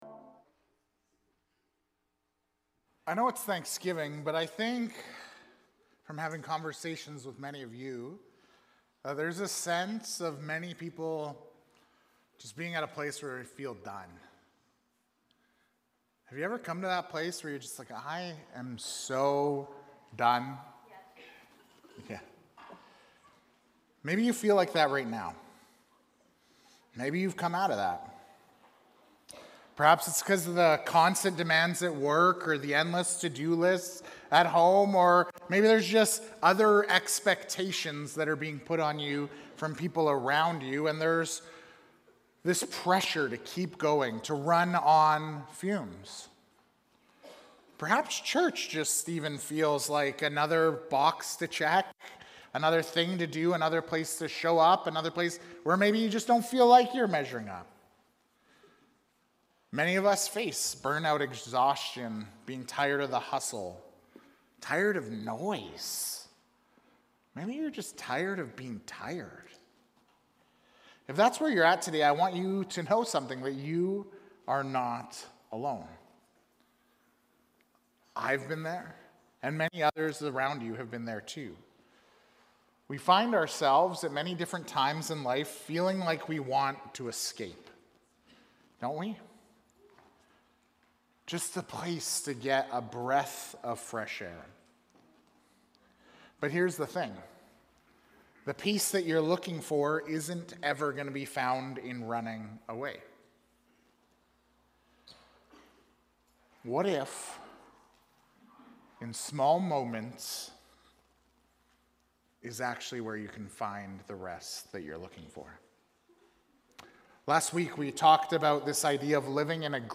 Immanuel Church Sermons | Immanuel Fellowship Baptist Church